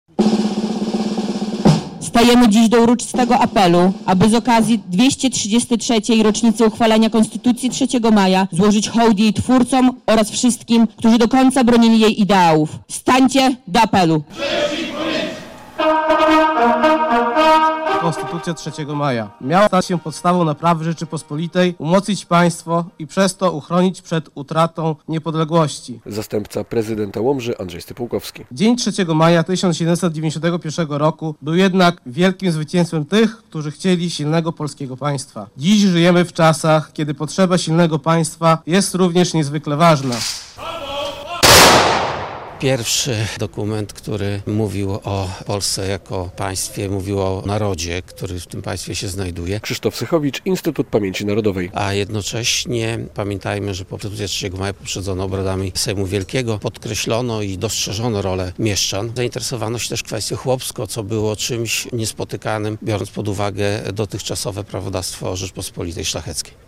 Obchody 233. rocznicy uchwalenia Konstytucji 3 Maja w Łomży - relacja